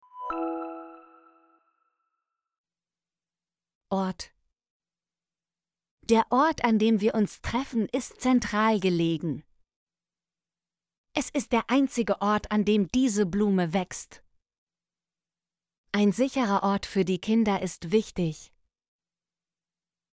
ort - Example Sentences & Pronunciation, German Frequency List